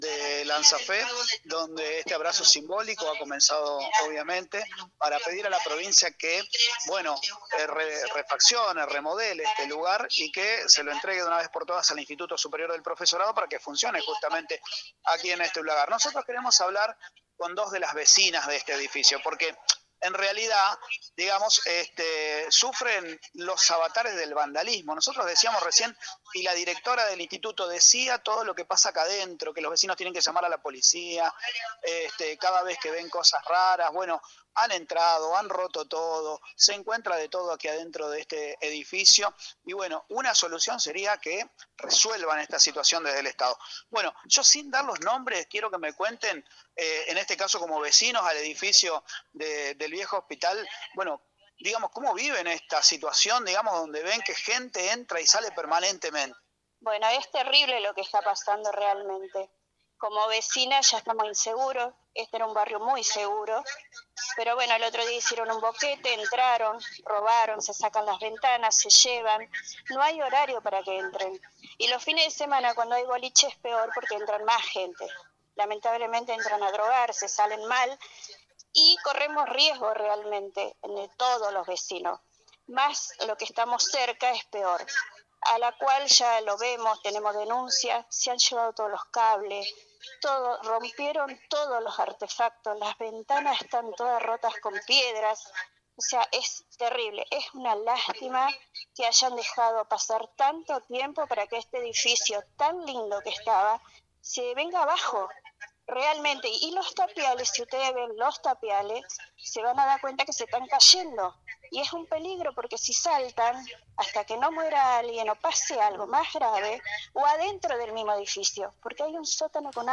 Dos vecinas del edificio ubicado en Bv. Irigoyen y Alem prestaron testimonio en  para mostrar su indignación por las cosas que suceden en ese lugar.